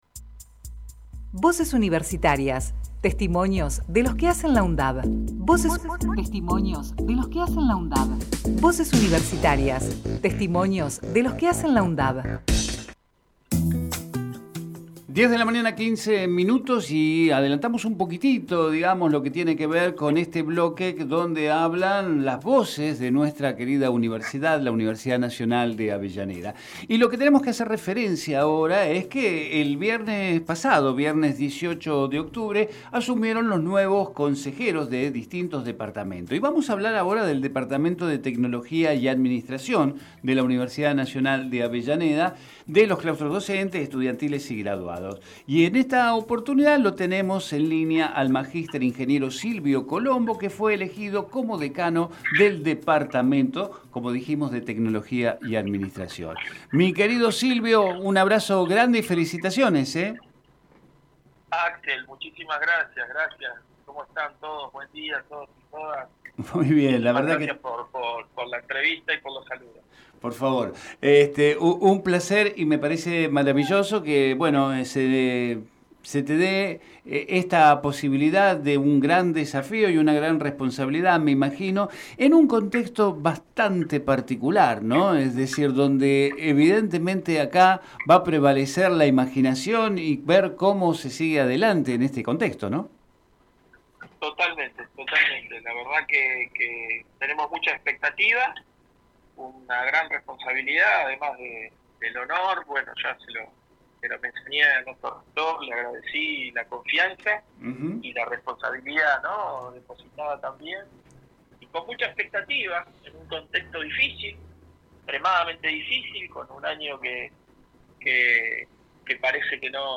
Compartimos con ustedes la entrevista realizada en Territorio Sur al Mg.